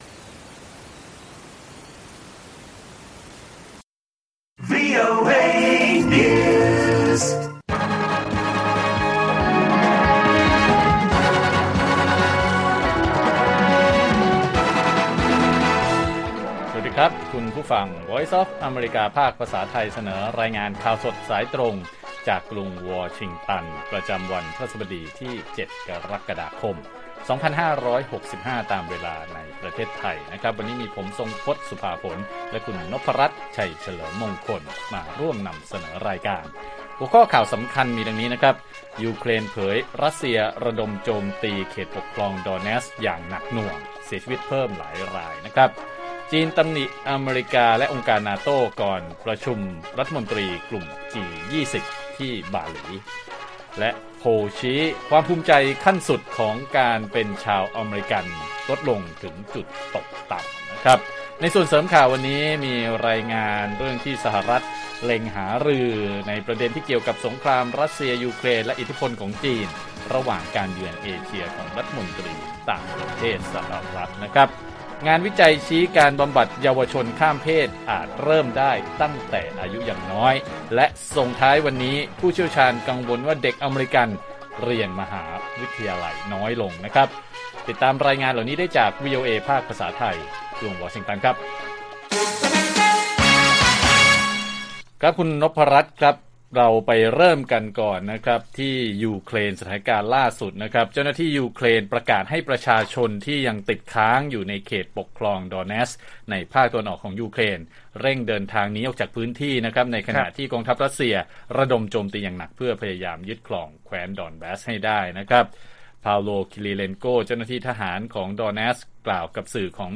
ข่าวสดสายตรงจากวีโอเอไทย 6:30 – 7:00 น. วันที่ 7 ก.ค. 65